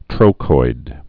(trōkoid, trŏkoid)